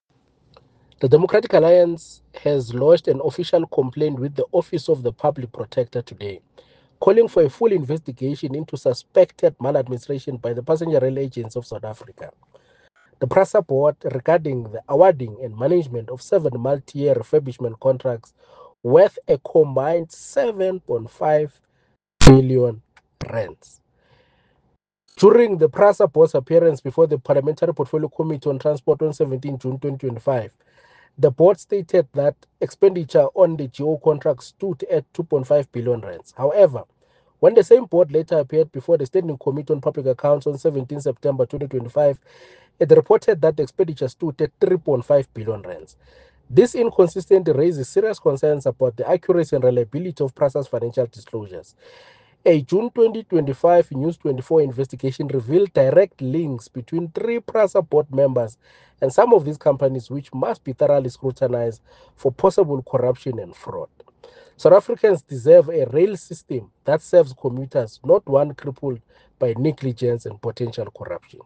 soundbite by Thamsanqa Mabhena MP.